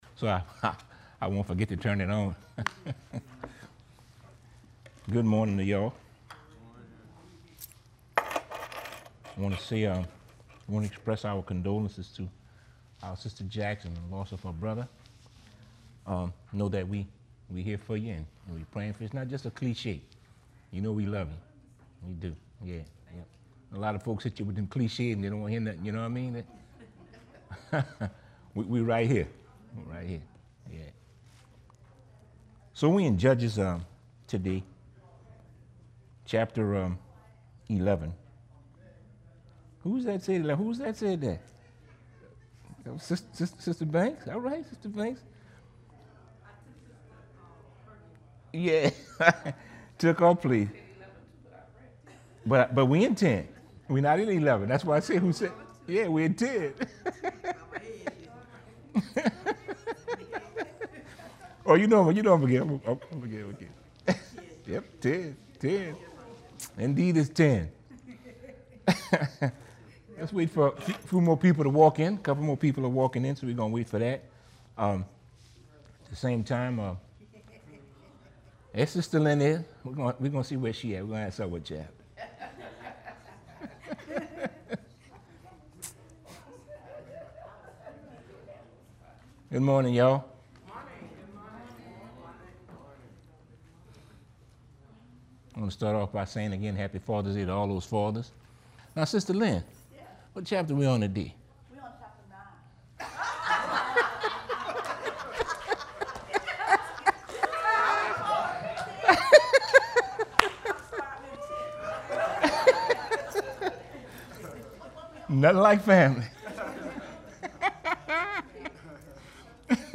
bible class